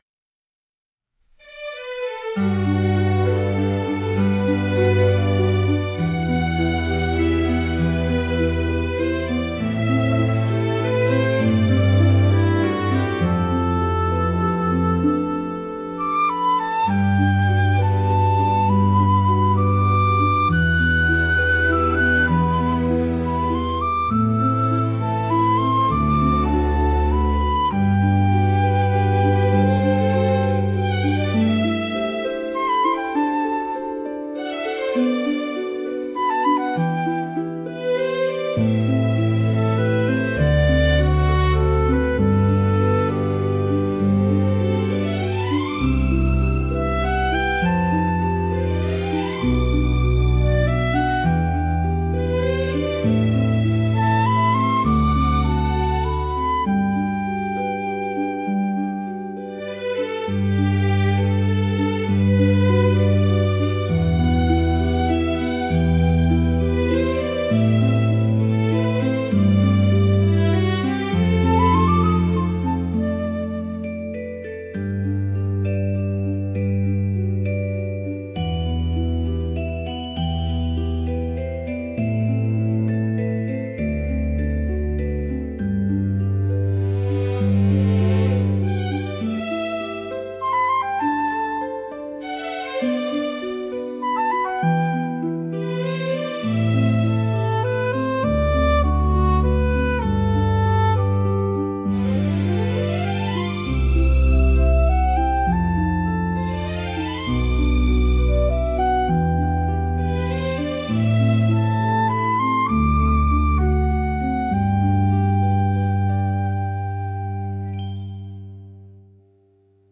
静かで清々しい空気感を演出しつつ、心に明るい希望や期待を芽生えさせる効果があります。